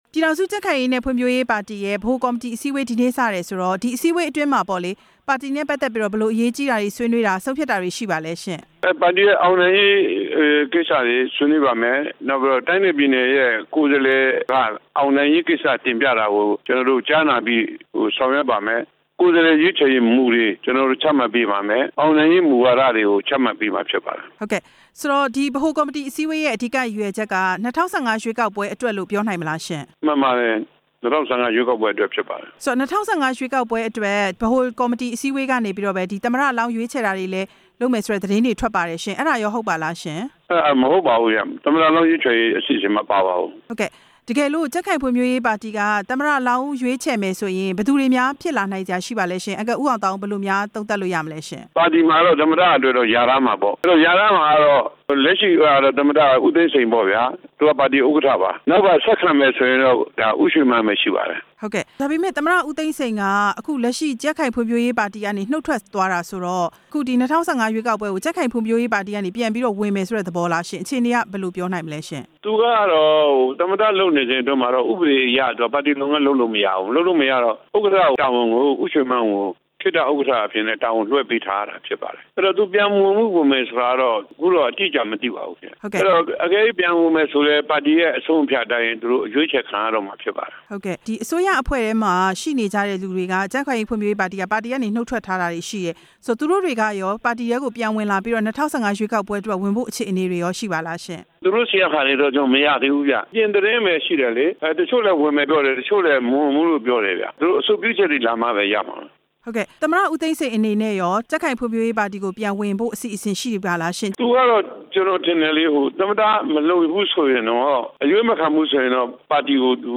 ဦးအောင်သောင်းနဲ့ မေးမြန်းချက်